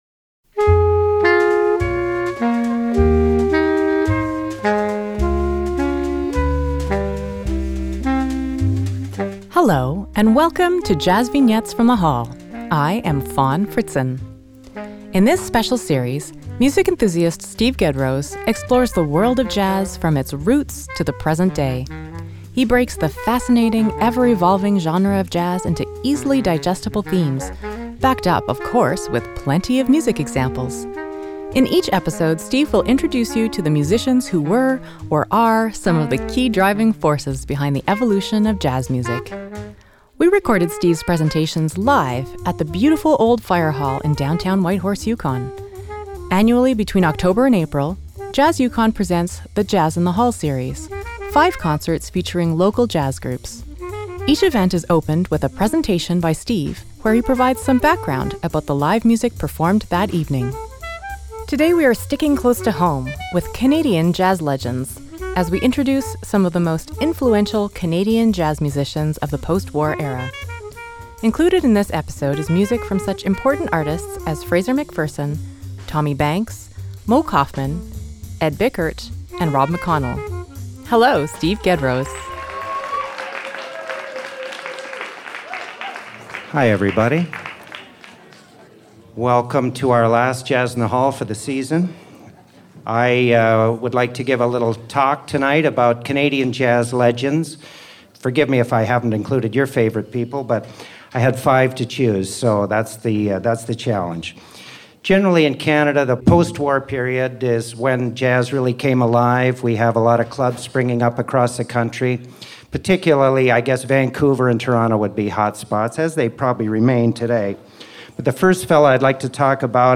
JVFTH04CanadianJazzLegends.mp3 57,852k 256kbps Stereo Comments